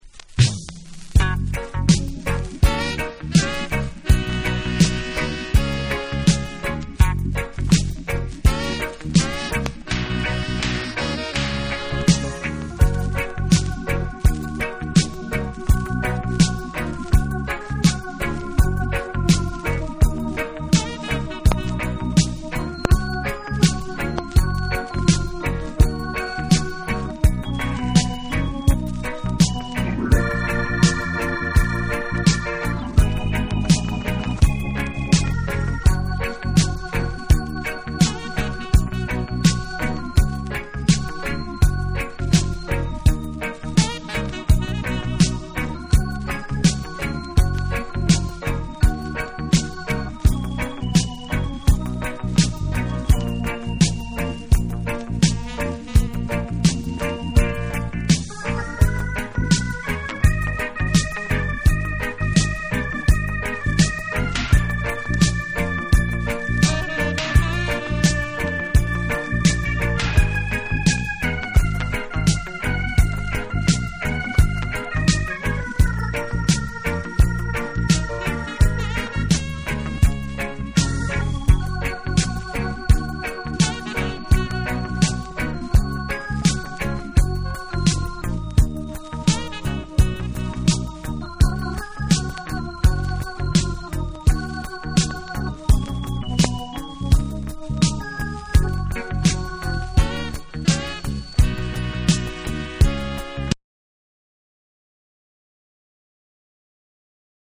※ジャマイカ盤特有のチリノイズはいります。
REGGAE & DUB / NEW RELEASE